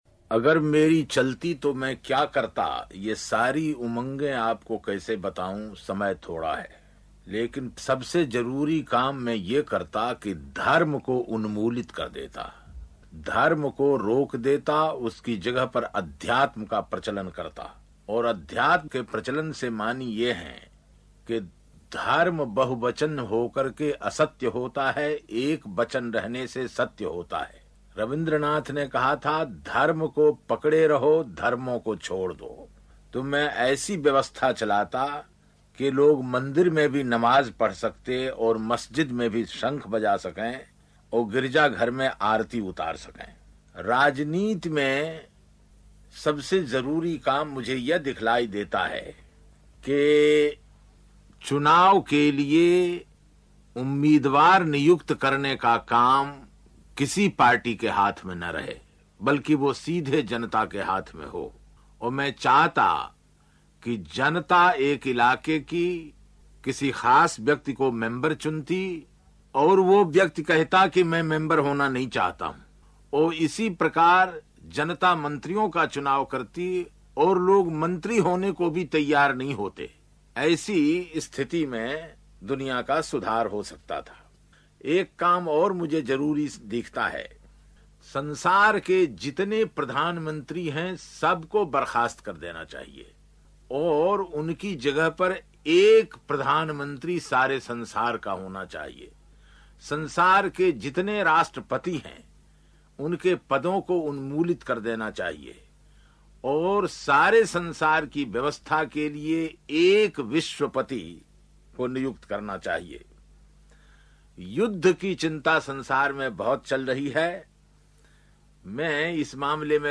24 अप्रैल को कवि रामधारी सिंह दिनकर की पुण्यतिथि है. इस अवसर पर उन्सुहीं की ज़ुबानी सुनें उनकी एक कविता.